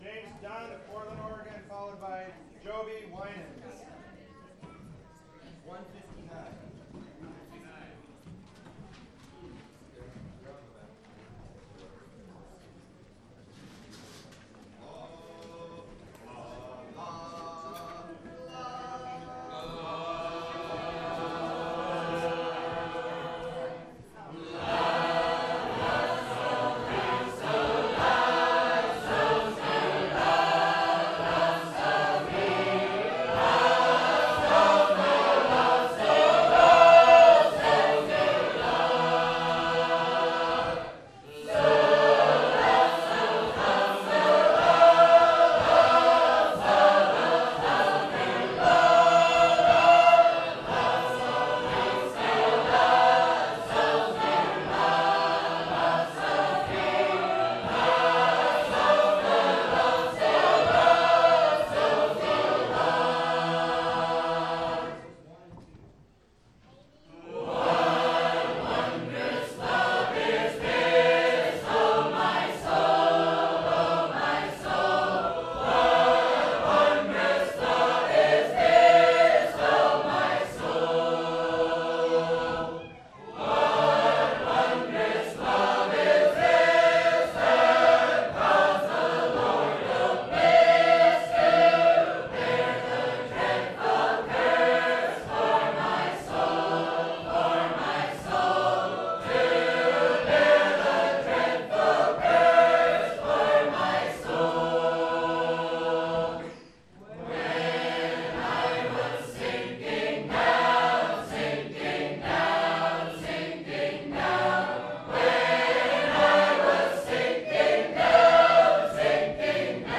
some Portland Sacred Harp Recordings to download, right click on links (cmd-click for mac). they are extremely large files. listen to some of these on SoundCloud (more coming soon)